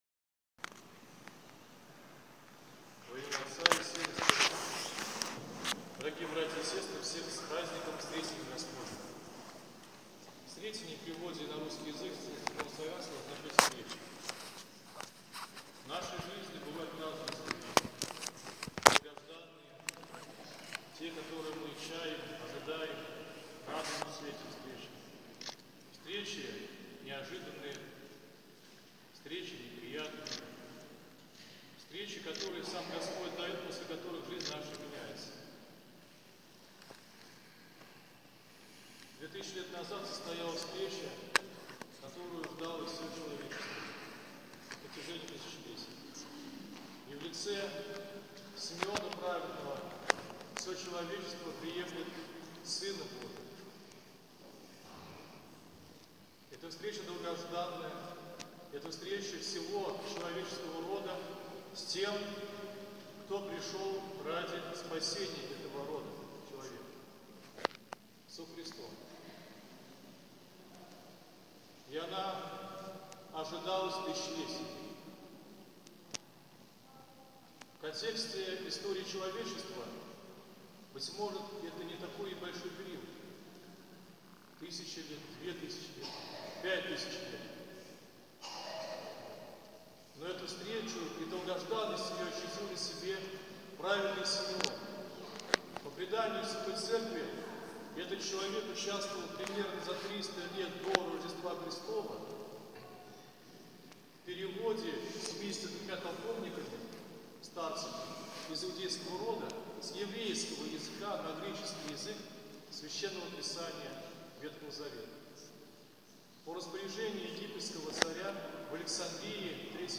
Затем с пастырским словом к собравшимся обратился иерей